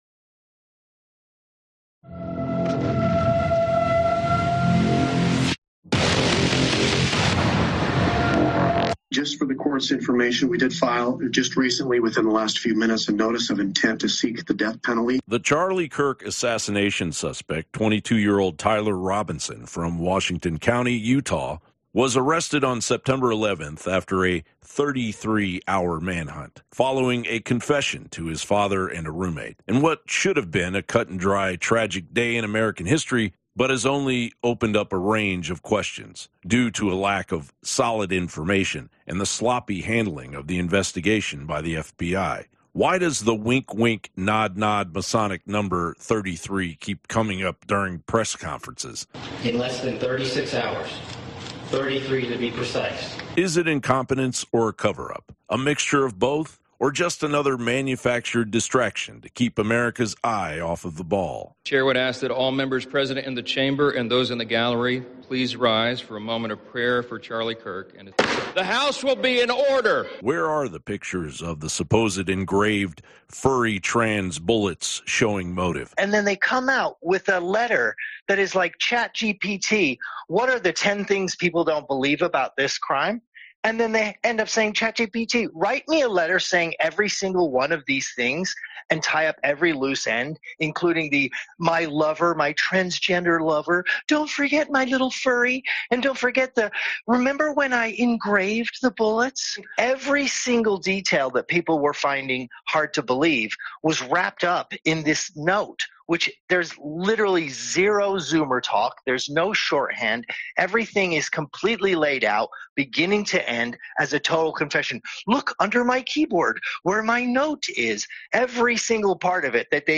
RENOWNED JOURNALIST LARA LOGAN HOSTS INFOWARS WAR ROOM LIVE IN-STUDIO — LEFT LOSES IT AFTER ABC CANCELS KIMMEL, TRUMP DECLARES ANTIFA ‘TERRORISTS’ & ERIKA KIRK TAKES OVER TURNING POINT USA